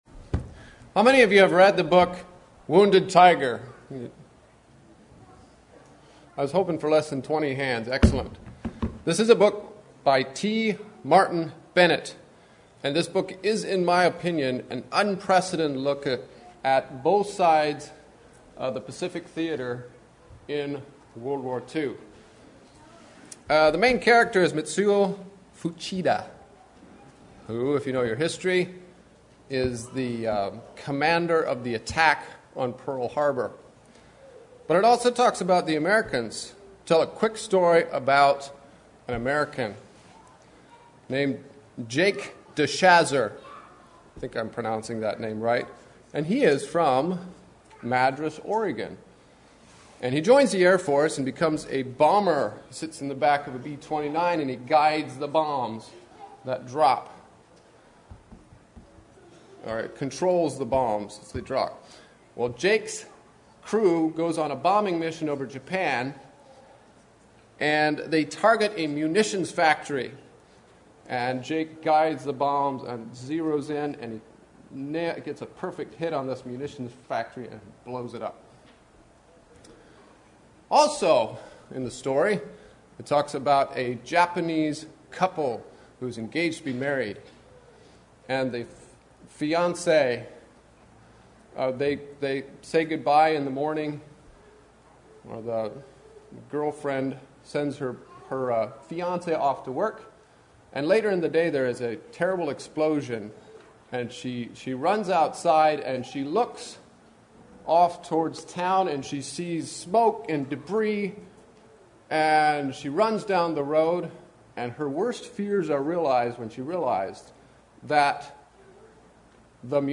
Home » Lectures » Book and Country Reports